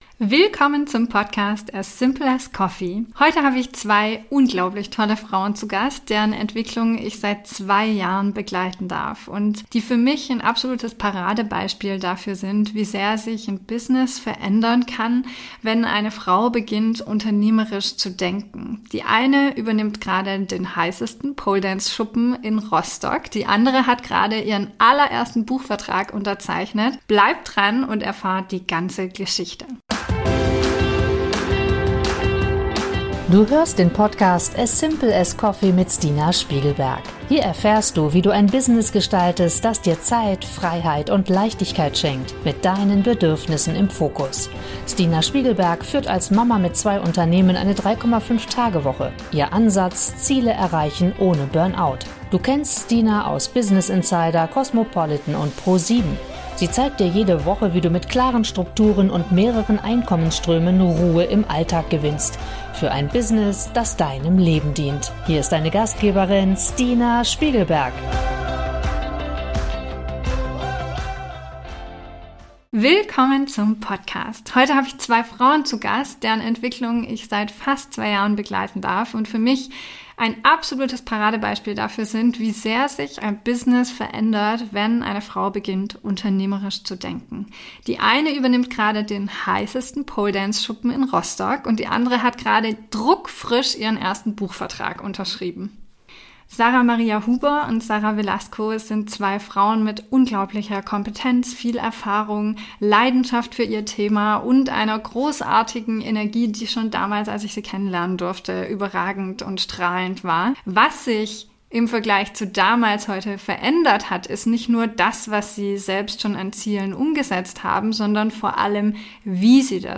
Zwei Frauen, zwei Jahre nach der Mastermind ~ As Simple as Coffee Podcast